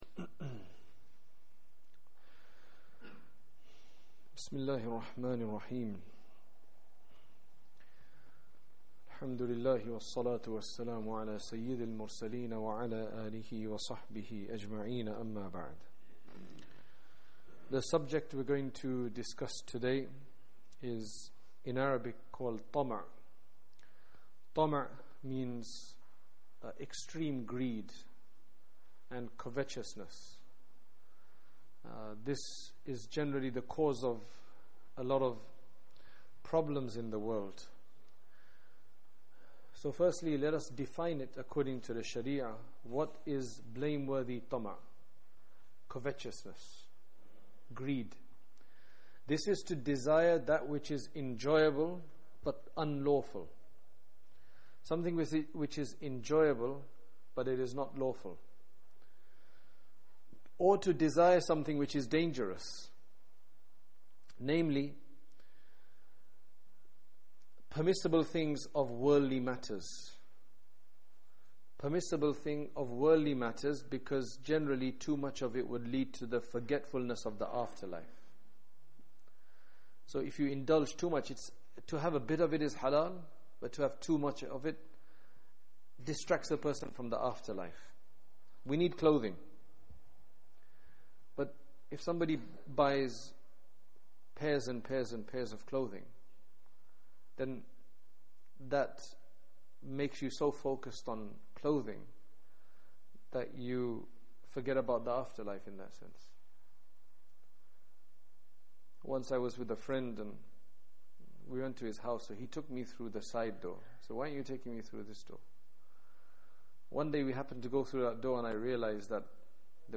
Recent Lectures